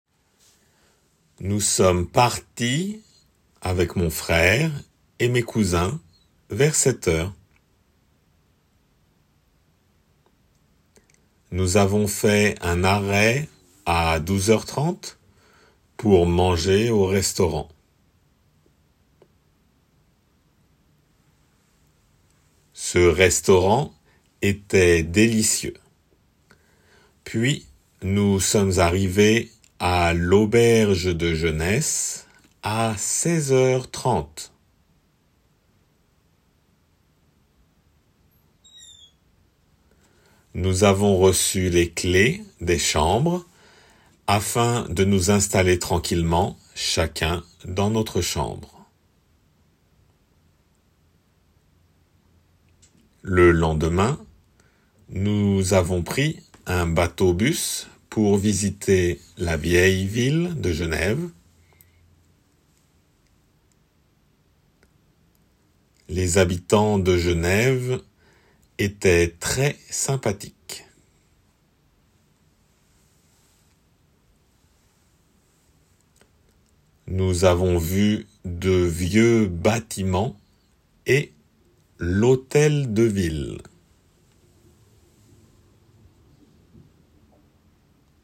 仏検過去問からです。　　　リスニング　練習用。
普通の速さで。